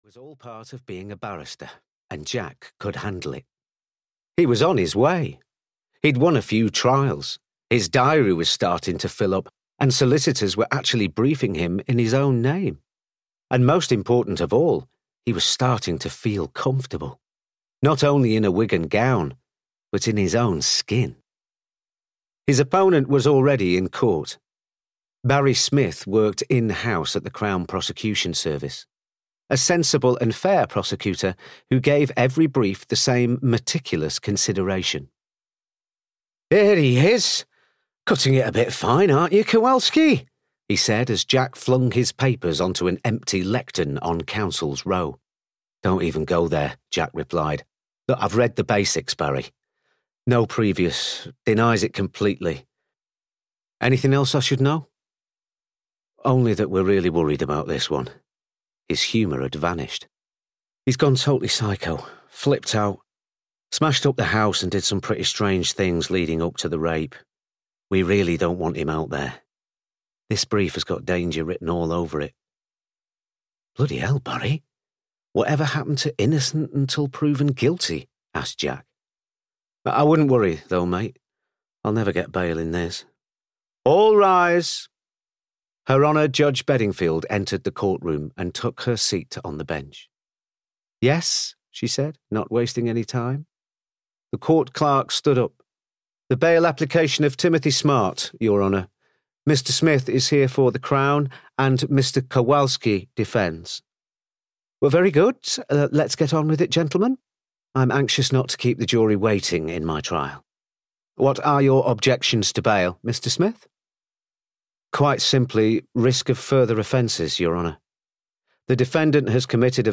Unconvicted (EN) audiokniha
Ukázka z knihy